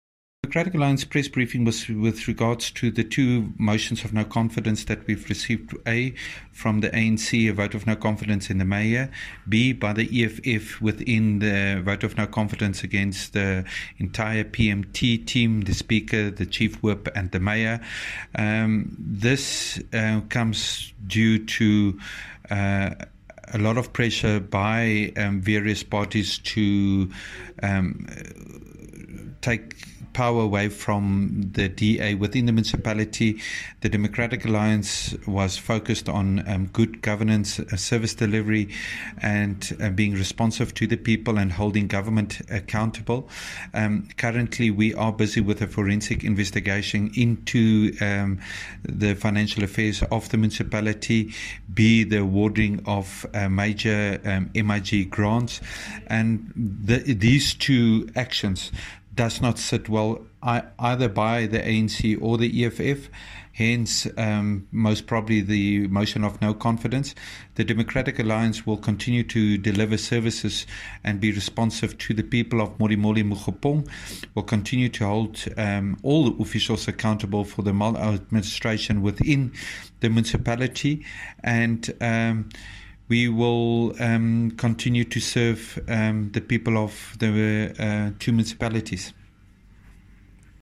Find attached voiceclips from Jacques Smalle, DA Limpopo Premier Candidate, in